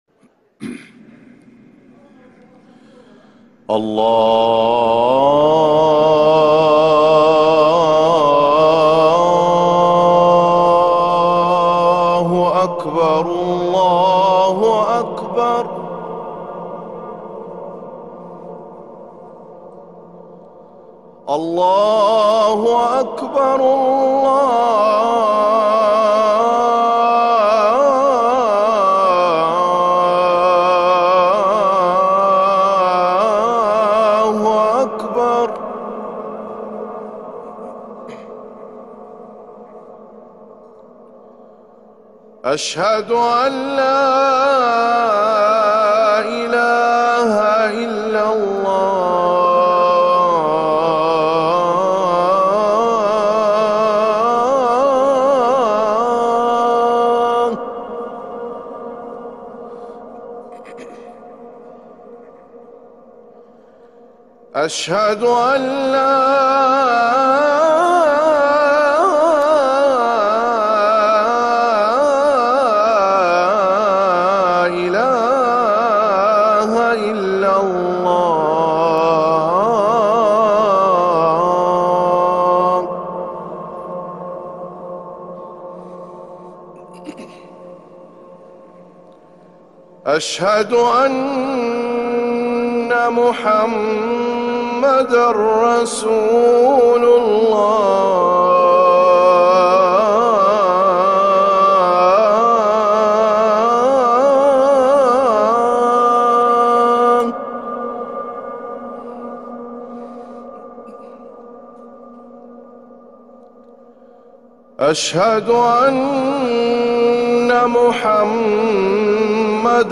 اذان الفجر
> روائع الأذان > ركن الأذان 🕌 > المزيد - تلاوات الحرمين